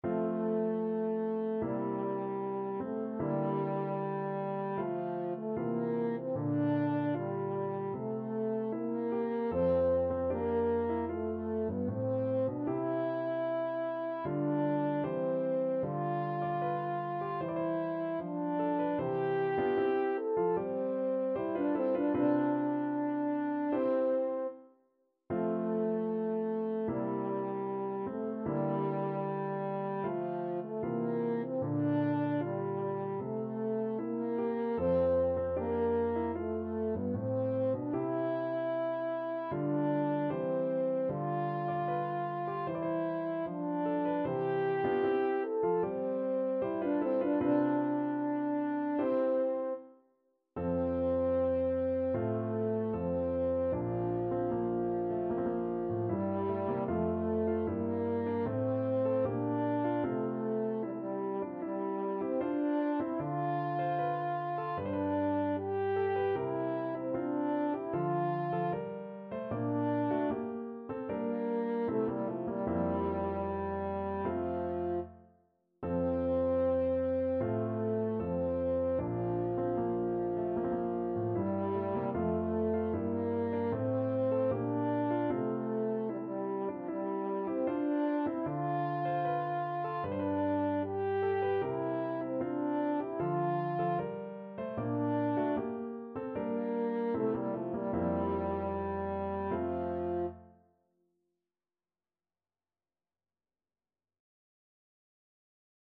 French Horn
F major (Sounding Pitch) C major (French Horn in F) (View more F major Music for French Horn )
4/4 (View more 4/4 Music)
Andante =76
Classical (View more Classical French Horn Music)
march_idomeneo_act3_HN.mp3